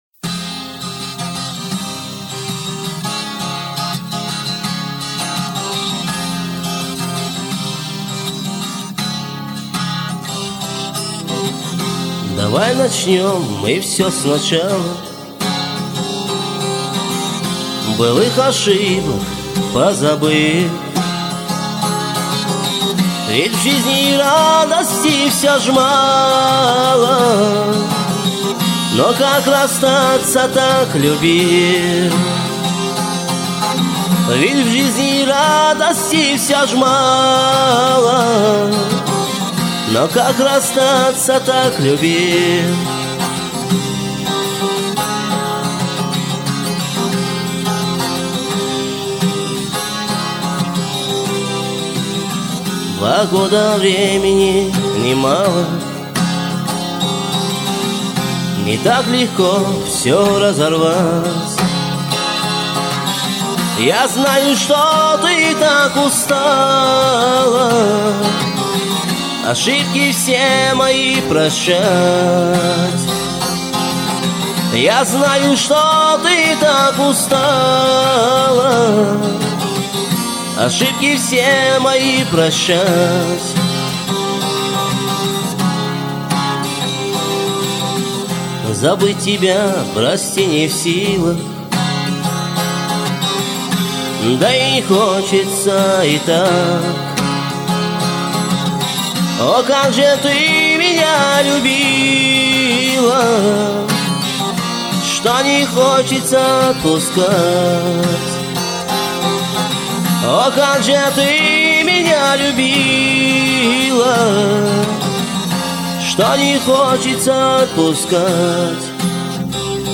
Ключевые слова: грусть, печаль, о ней, про любовь